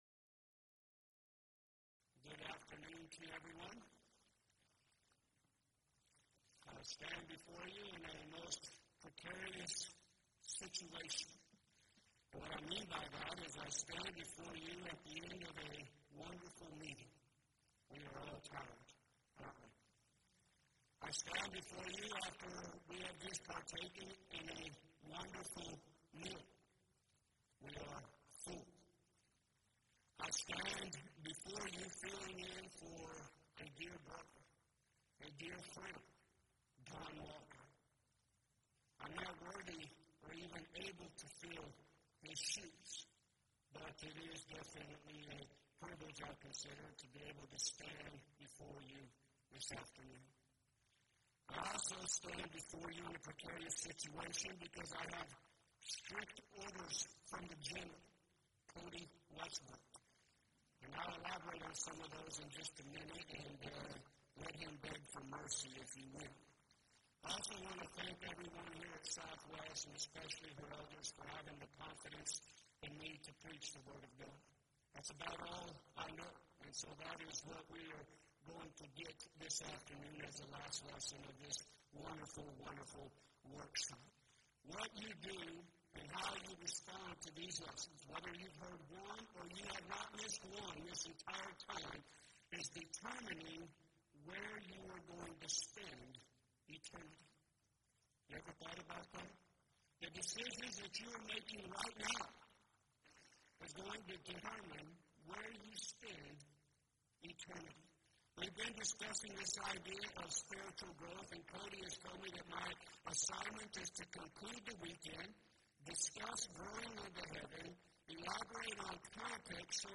Event: 2nd Annual Arise Workshop
lecture